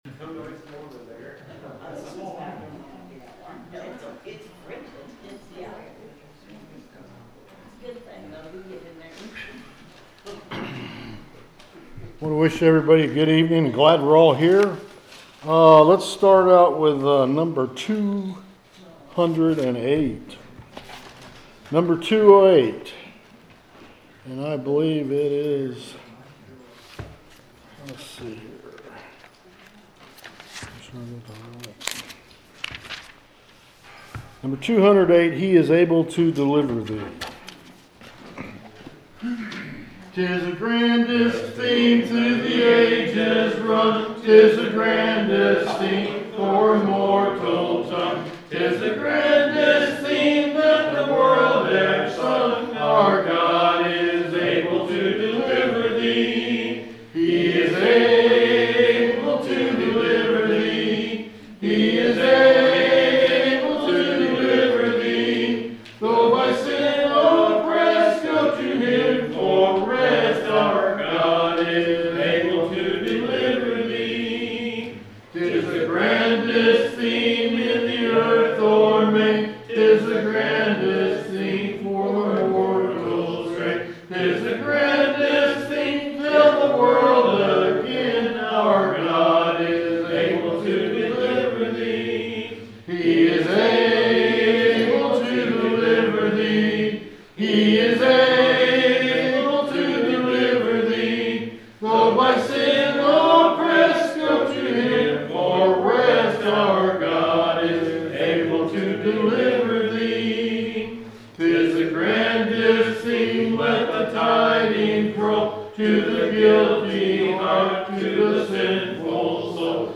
The sermon is from our live stream on 1/14/2026